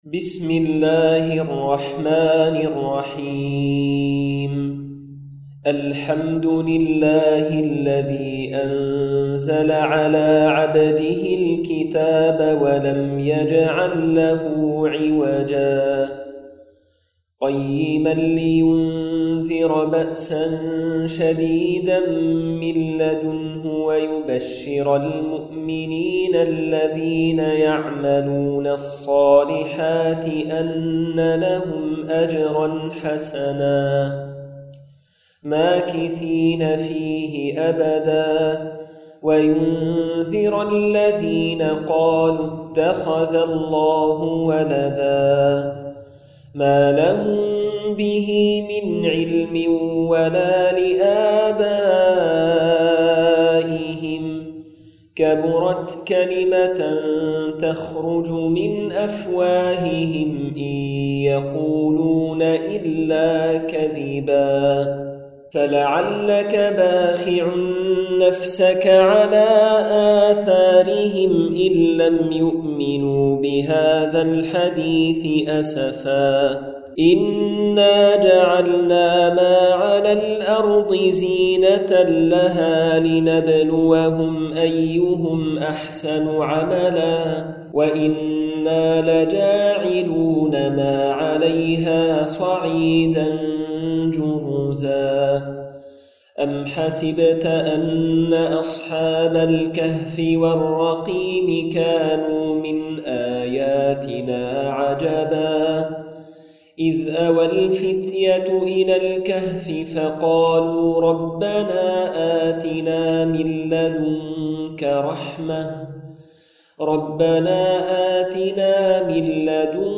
تلاوة لسورة الكهف بالصوت الشجي
تلاوات